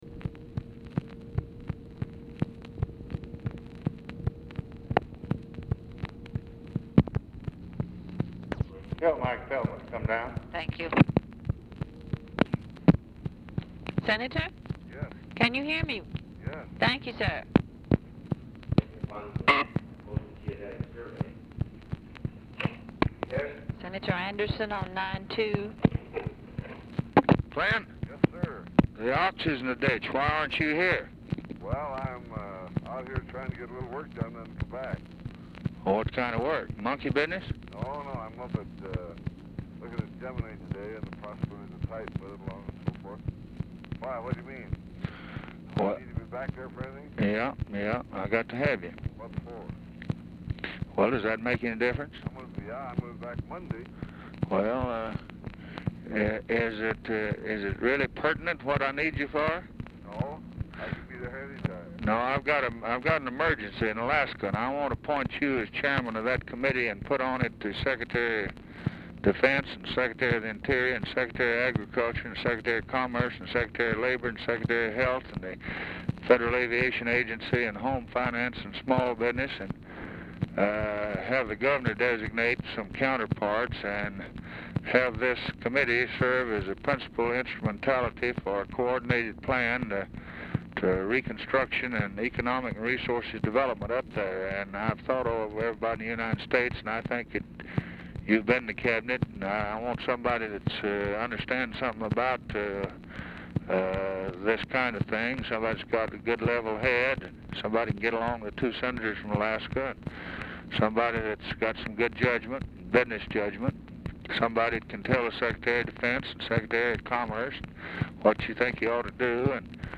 Telephone conversation # 2802, sound recording, LBJ and CLINTON ANDERSON, 4/1/1964, 1:35PM | Discover LBJ
LBJ IS MEETING WITH ALASKA SENATORS E. L. BARTLETT, ERNEST GRUENING AT TIME OF CALL; ANDERSON IS IN DENVER
Format Dictation belt
Specific Item Type Telephone conversation